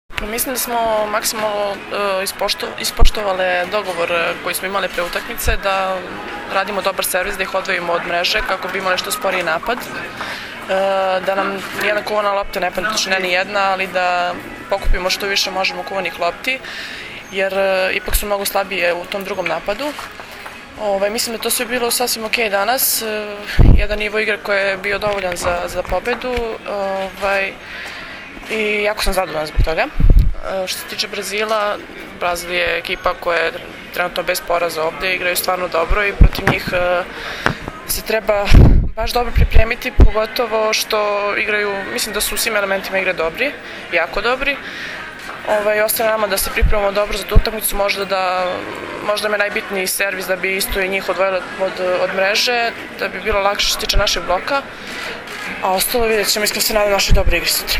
IZJAVA MILENE RAŠIĆ